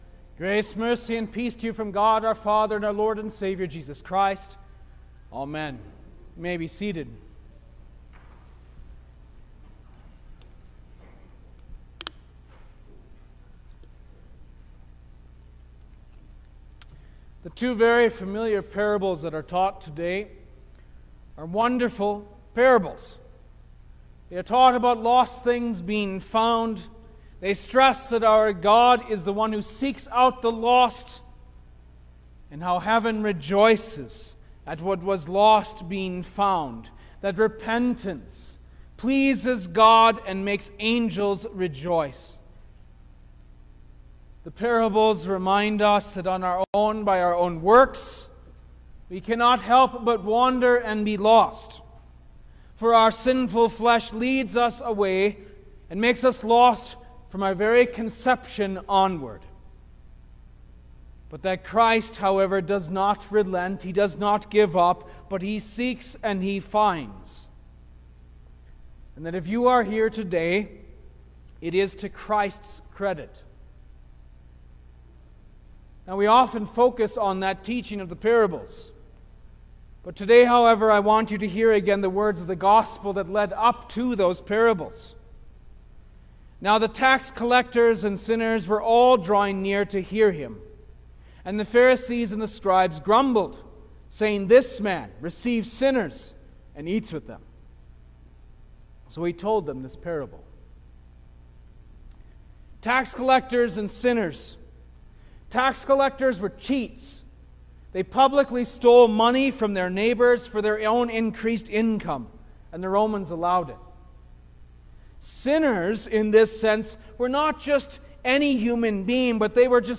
July-3_2022_Third-Sunday-after-Trinity_Sermon-Stereo.mp3